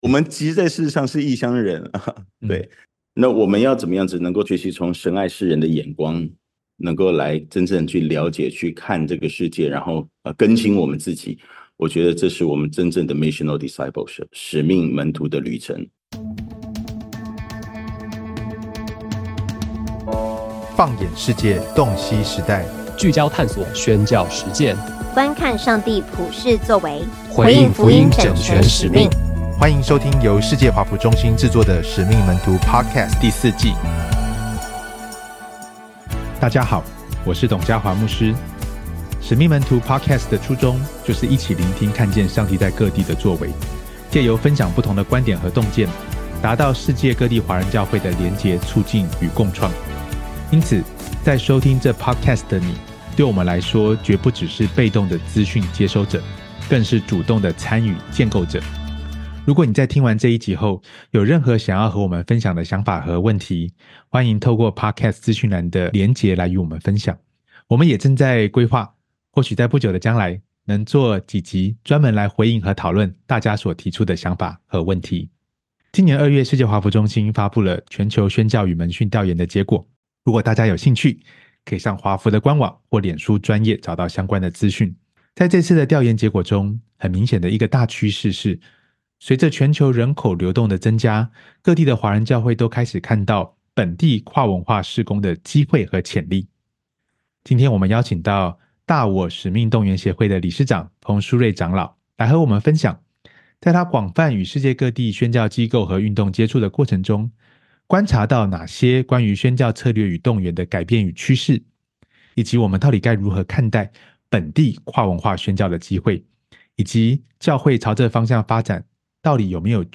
每一集，我們會與一位來自全球不同城市的華人基督徒領袖對話，一起探索當代關鍵的議題和挑戰。